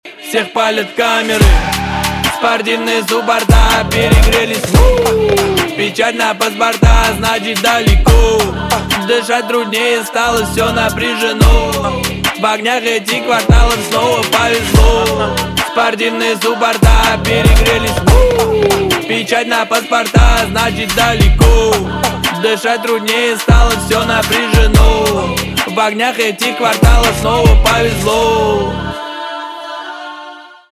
рэп
русский рэп
хор
басы